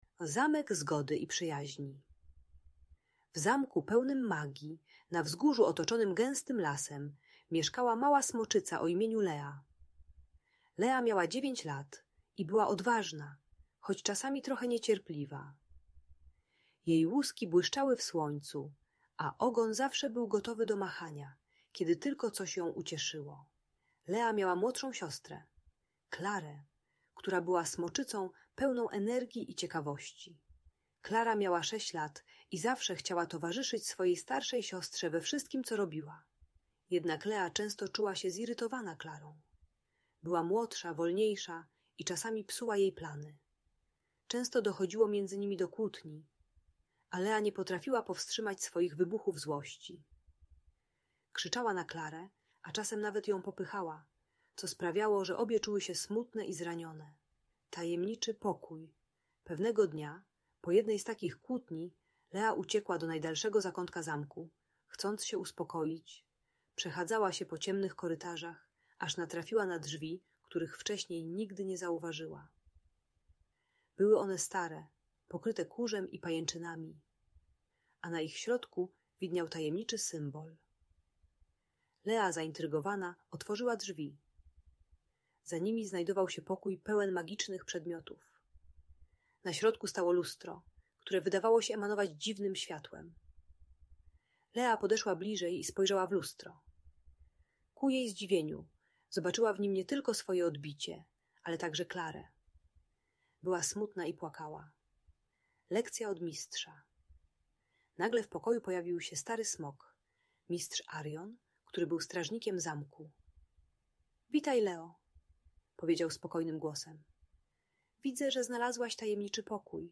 Zamek Zgody i Przyjaźni - Audiobajka dla dzieci